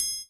percussion 13.wav